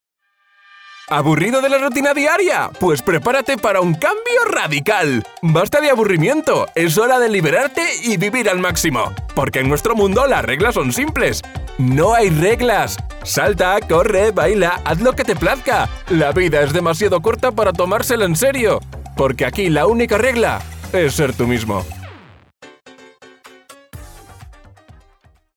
Género: Masculino
Comercial